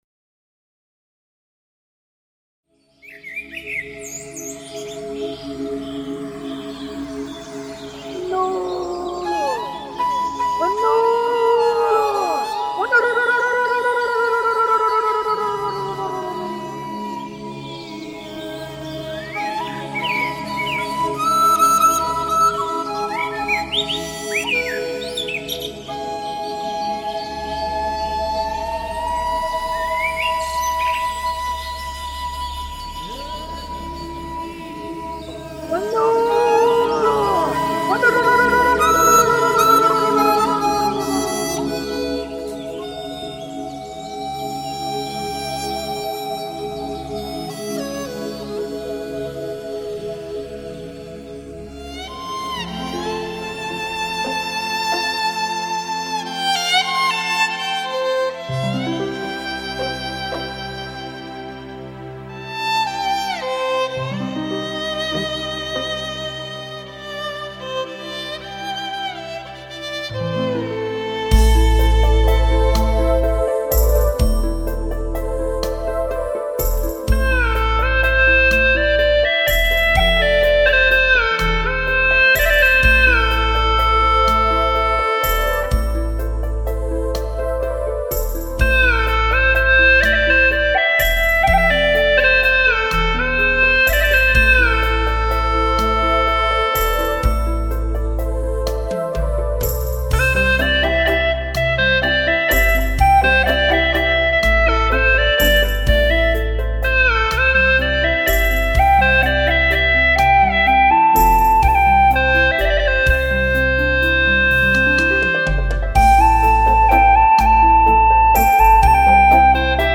葫芦丝演奏